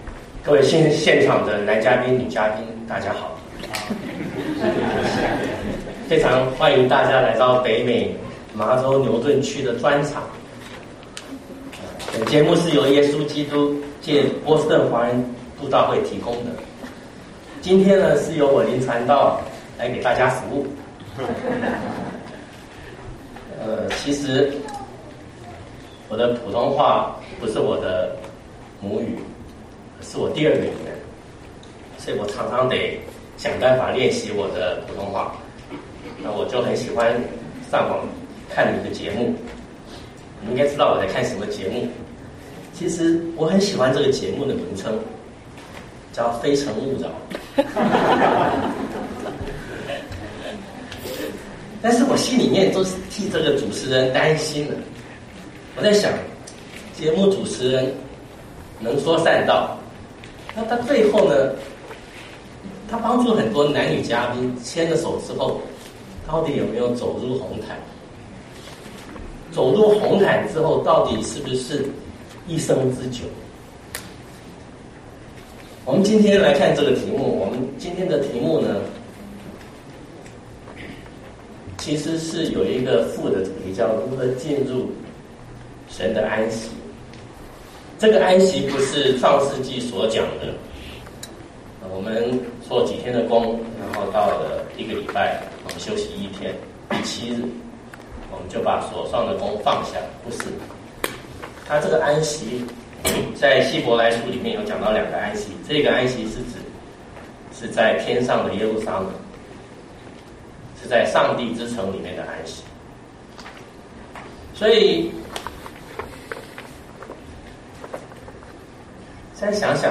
牛頓國語崇拜